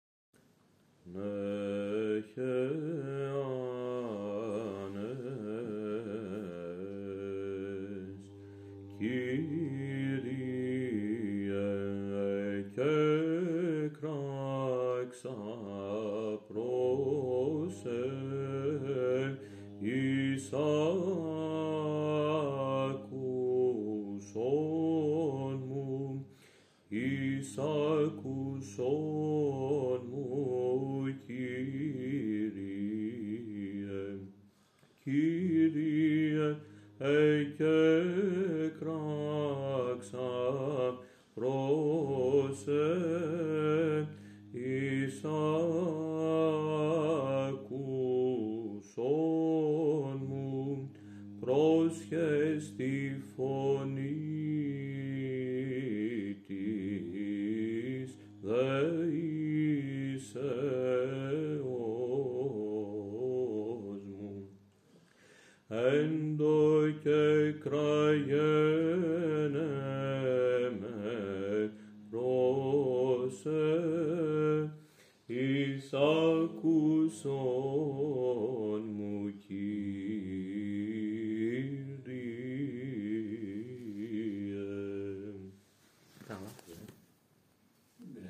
Ψαλτική τέχνη σε μοναστήρια και ενορίες της Θεσσαλονίκης.
dc.subject.lcshΒυζαντινή μουσικήel